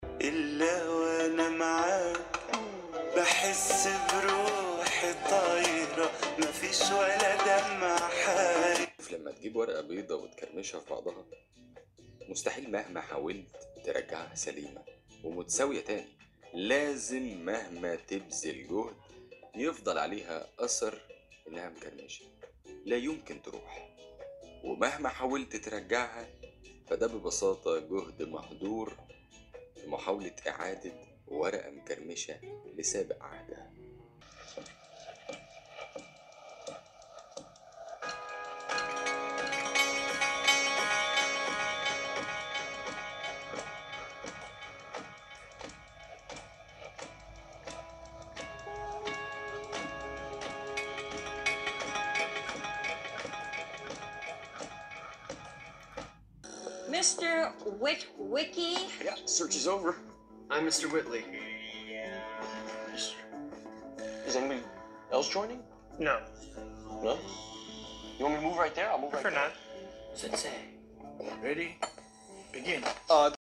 الإزعاج و صوت السماعات
نوضح في هذا الجزء مدي قوة الصوت و جودته مع تقديم الإزعاج الخاص بالتبريد في الأوضاع المختلفة و يتم القياس من مسافة 25 سم وهو ما يمثل بعد رأس المستخدم عن الجهاز لمحاكاة الضوضاء الفعلية من الجهاز و قوة السماعات كذلك
التجربة الصوتية مع تفعيل تقنيات Dolby
صوت السماعات واضح و ربما أكثر نقاء من جهاز GK5 و يمكن الحصول علي صوت أفضل و درجات أعلي من الـ Bass عن طريق تخصيص البرنامج الملحق مع الجهاز وهو جيد  للألعاب و الموسيقي و الأغاني و الأفلام ، الـ Bass مقبول  و الـ Triple جيد جدا  ولكن في هذه الفئة السعرية لا تطمح إلي أكثر من هذا  !
gigabyte-Aero-X16-speakers-test-sample-dolby-enabled.mp3